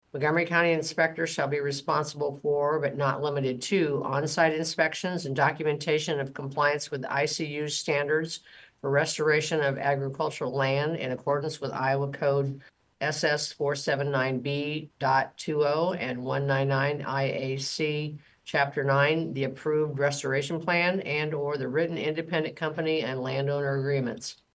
Supervisor Donna Robinson read the contract, which in part states…